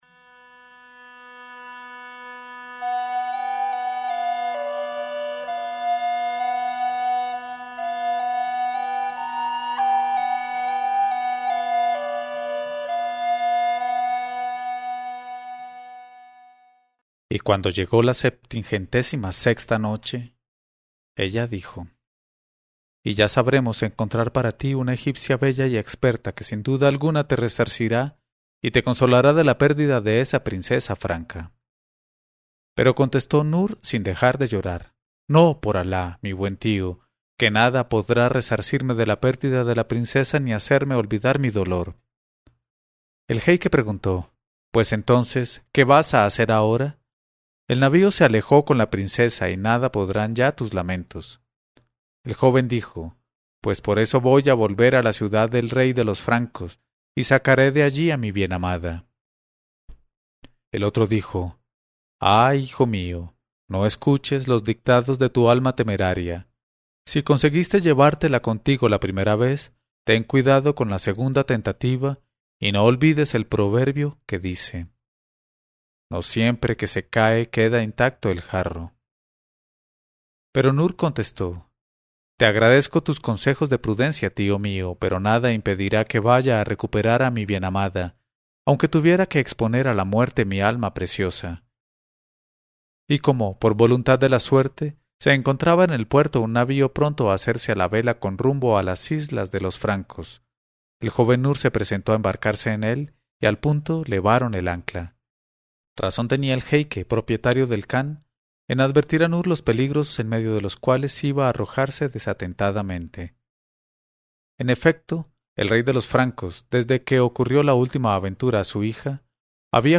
Una lectura en voz alta de Las mil noches y una noche. Una noche a la vez, "hasta que Schehrezada vea aparecer la mañana y calle discretamente".